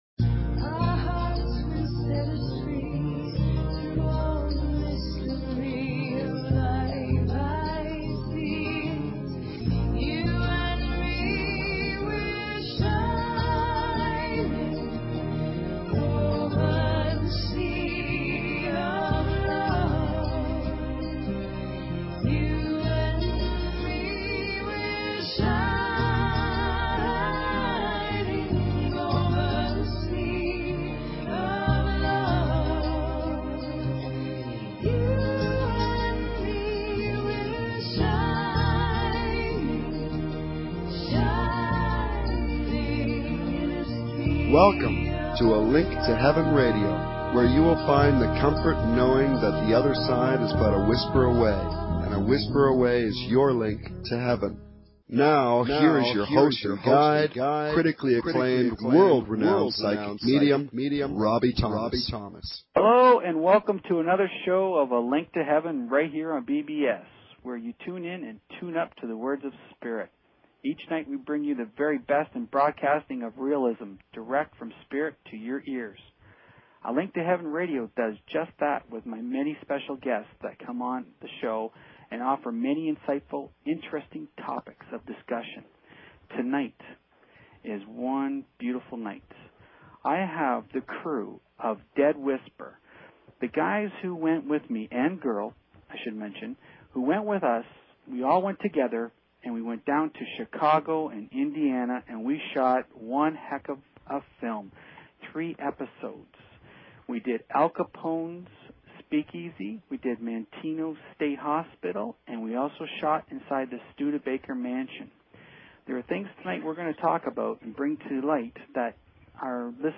Talk Show Episode, Audio Podcast, A_Link_To_Heaven and Courtesy of BBS Radio on , show guests , about , categorized as